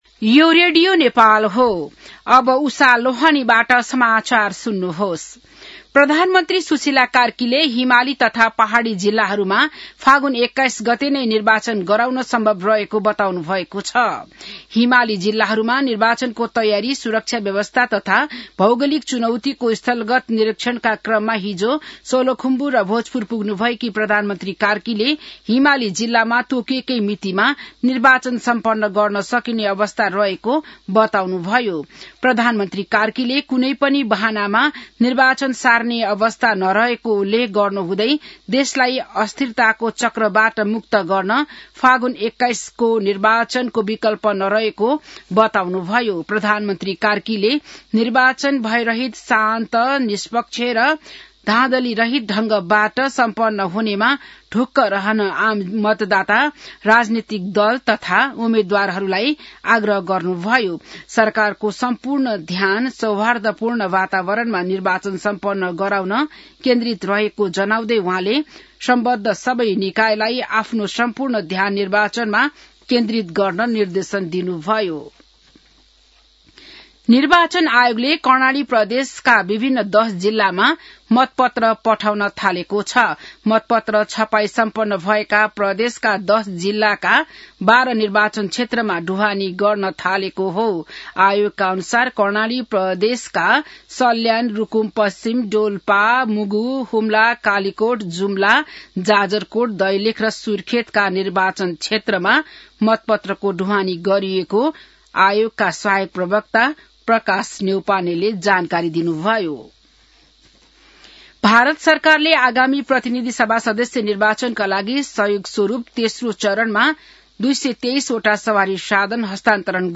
बिहान १० बजेको नेपाली समाचार : १ फागुन , २०८२